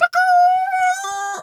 chicken_2_bwak_06.wav